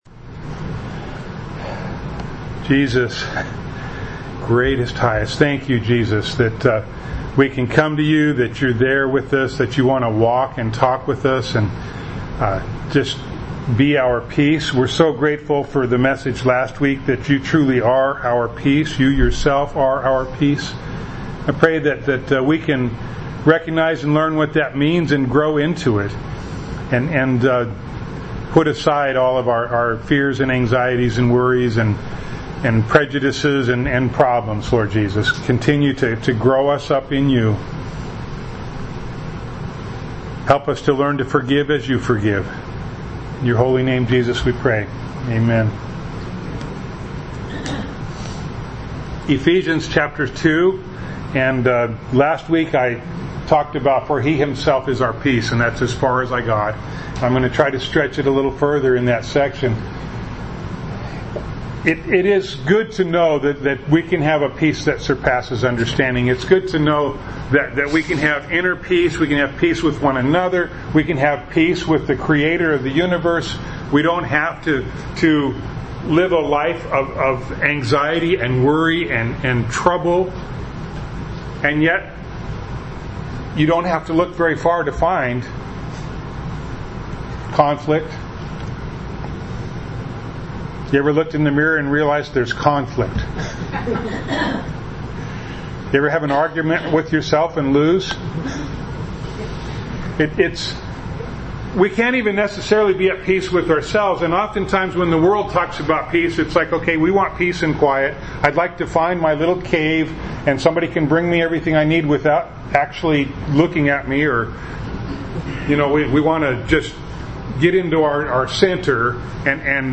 Passage: Ephesians 2:14-16 Service Type: Sunday Morning